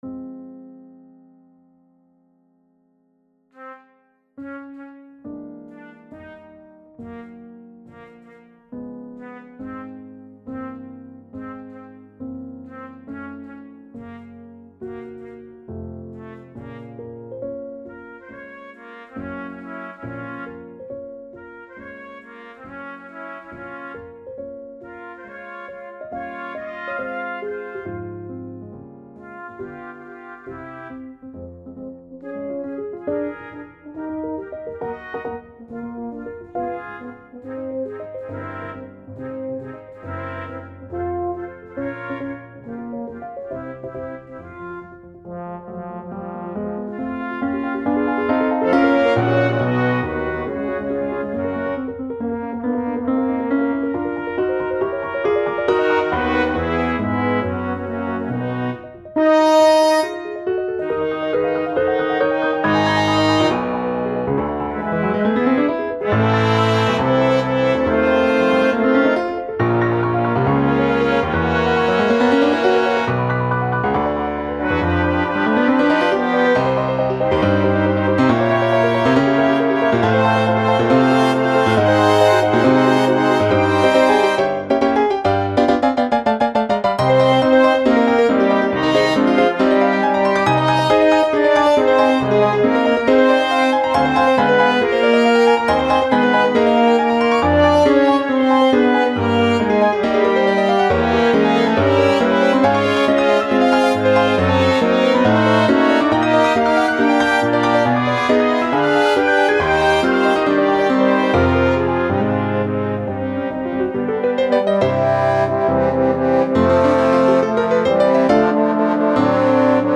Number of voices: 4vv Voicing: SATB Genre: Secular, Chanson
Language: French Instruments: Piano